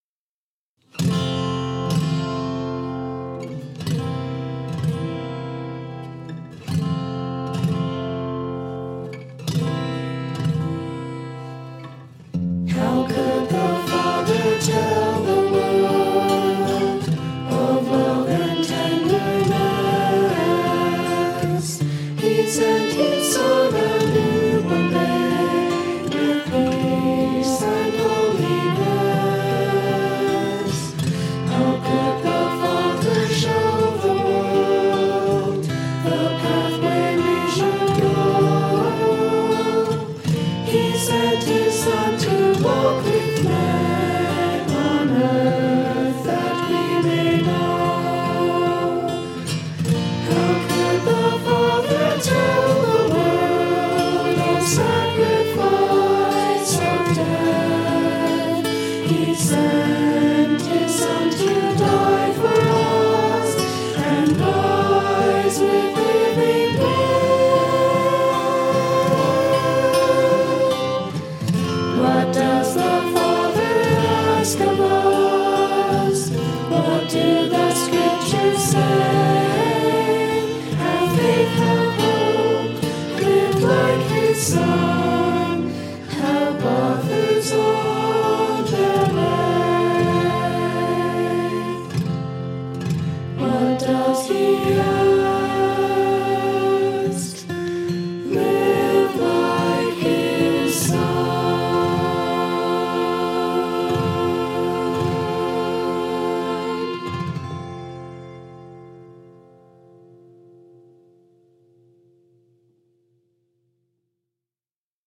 vocals: Sanderson Family (all harmonies improvised)
guitar
violin
This recording is the first to feature my new guitar, a Martin D-28, which I bought last month.
Unfortunately this song is in the key of F, and the only chord that I am not playing as a bar chord is C7, so my left hand gets really sore by the end of the song.